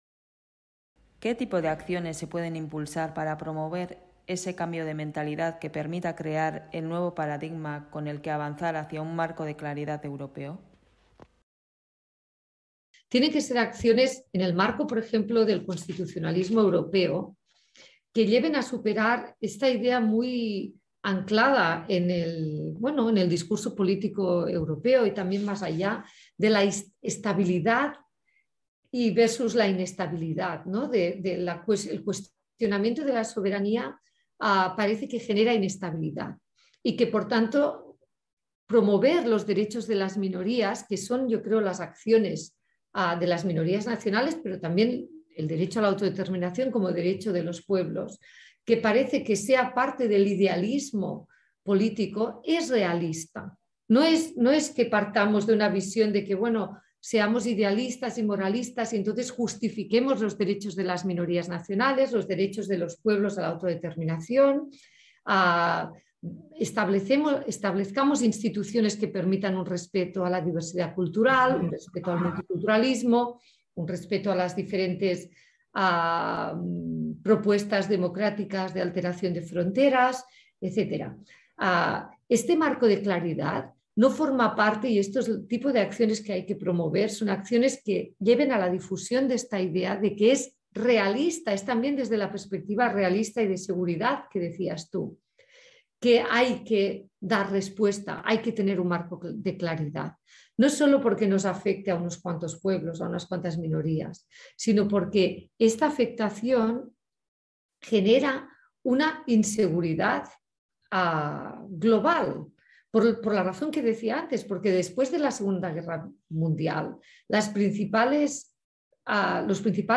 Las dos expertas coinciden en la necesidad de un marco de claridad europeo para la resolución de los conflictos territoriales de soberanía. Dado que el documento elaborado por el equipo de investigación de Eusko Ikaskuntza contempla la necesidad de un nuevo paradigma de acercamiento a estos conflictos, se interrogan sobre las bases de ese nuevo paradigma y las acciones para promoverlo.